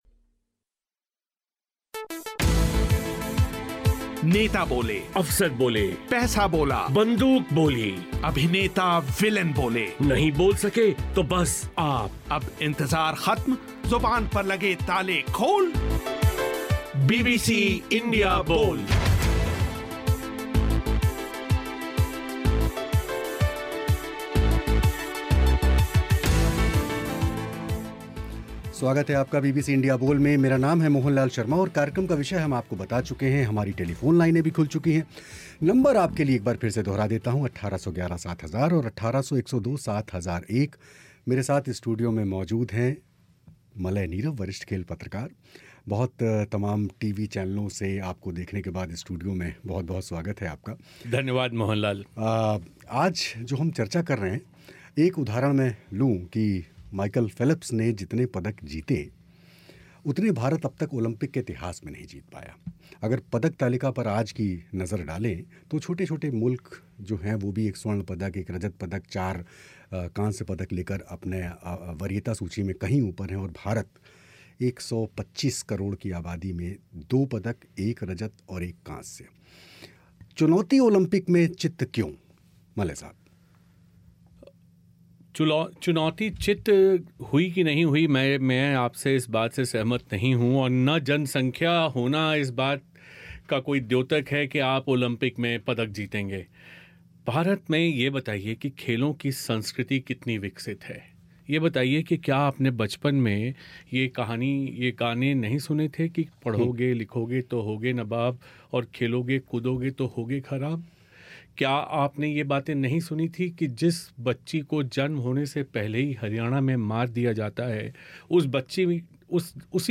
रियो में अब तक भारत को सिर्फ एक रजत और एक कांस्य हासिल हुआ है. सवा सौ करोड़ की आबादी वाले भारत में ना पैसों की कमी है ना खिलाड़ियों में दमखम की फिर भी क्यों ओलंपिक की चुनौती में चित्त हो जाता है भारत बीबीसी इंडिया बोल में इसी पर हुई चर्चा.